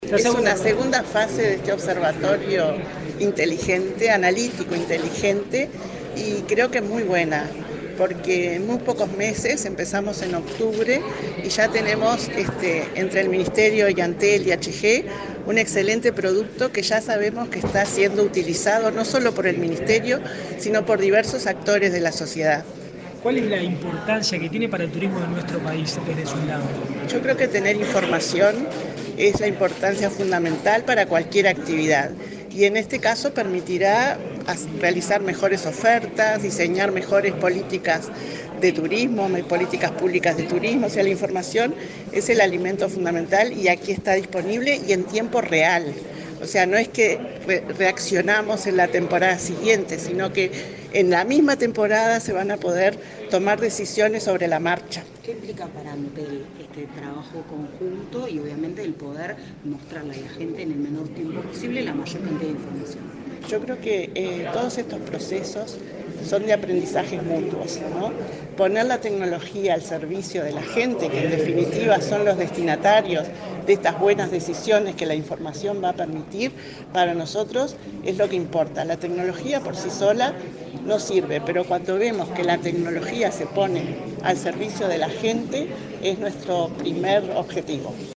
Declaraciones de la presidenta de Antel, Anabella Suburú
Declaraciones de la presidenta de Antel, Anabella Suburú 28/06/2024 Compartir Facebook X Copiar enlace WhatsApp LinkedIn El Ministerio de Turismo y Antel presentaron, este viernes 28 en Montevideo, los principales logros de la segunda etapa del proyecto Observatorio de Turismo Inteligente. La titular del ente de las telecomunicaciones, Anabella Suburú, dialogó con la prensa acerca de esta temática.